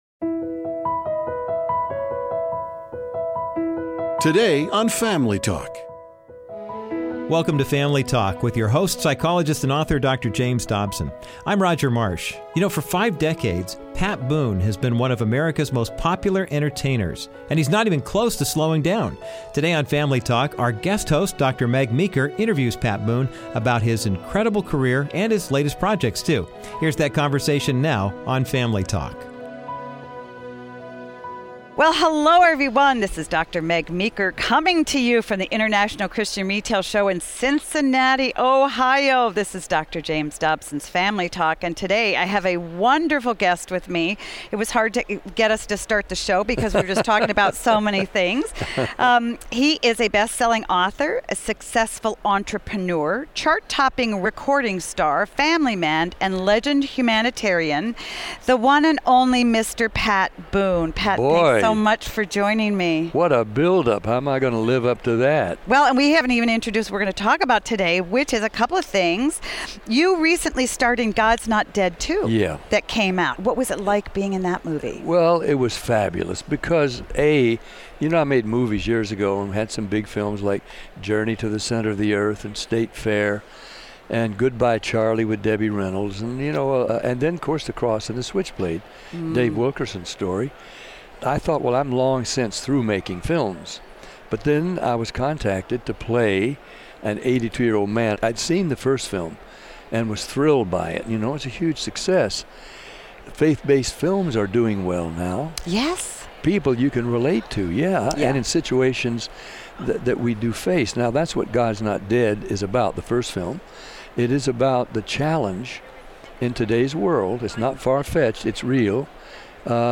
Dr. Meg Meeker talks with Pat about his incredible career and latest projects. From meeting Elvis to starring in blockbuster movies to recording pop hits, Pat Boone’s success has been amazing.